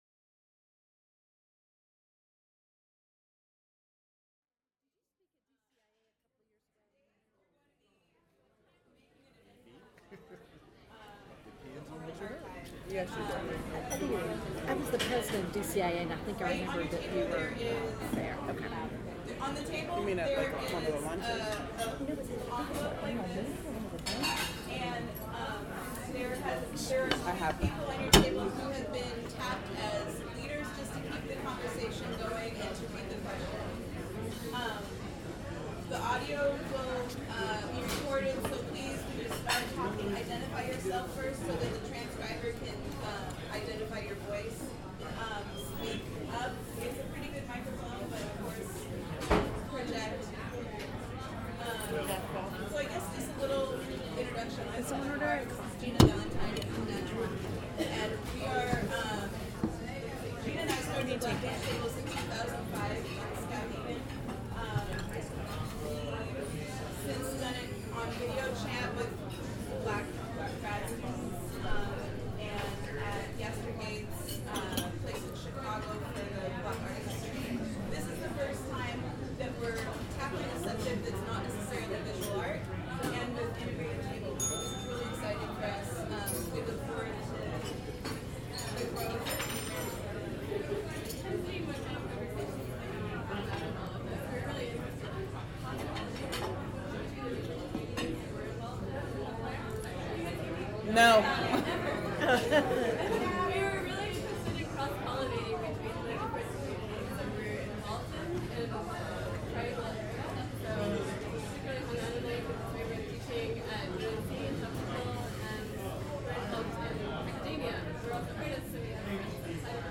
Type sound recording-nonmusical Extent 1:09:02 Publisher Black Lunch Table
oral history